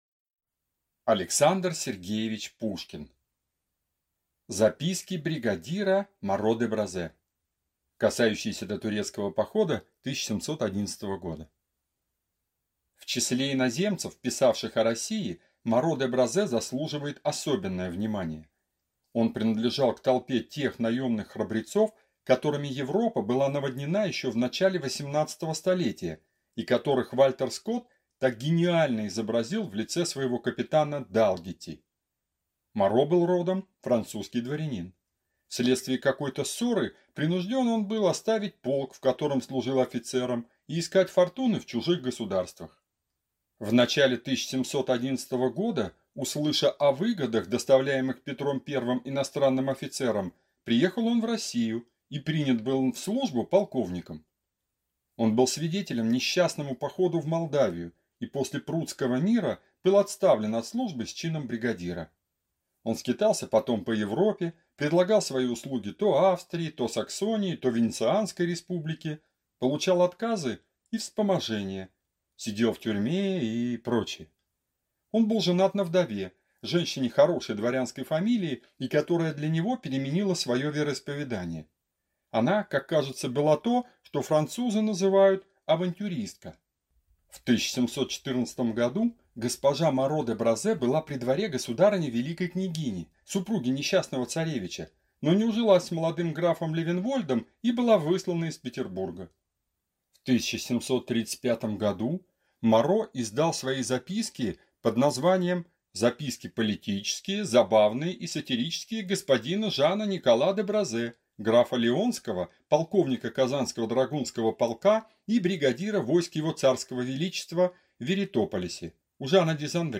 Аудиокнига Записки Моро-де-Бразе | Библиотека аудиокниг